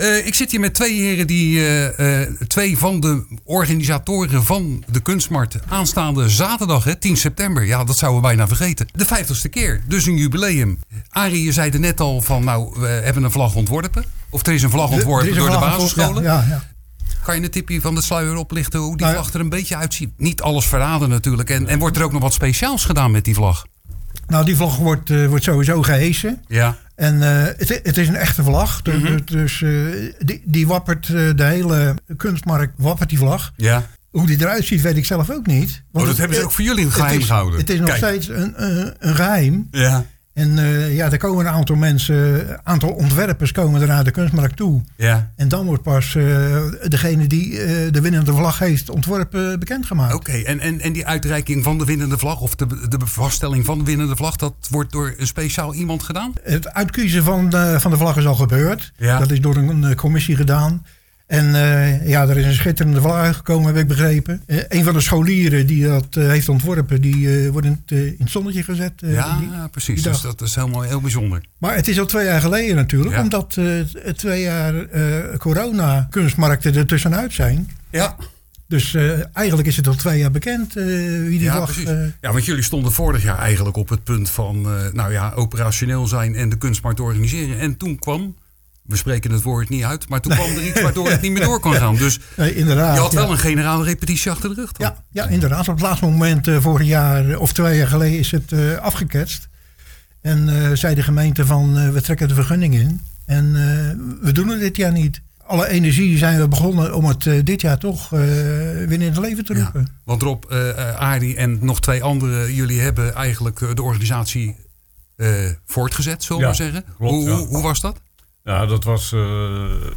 In�de�Kletskoekstudio�van�Radio�Capelleontvingen�wij�twee�van�de�leden